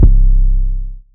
Pony 808.wav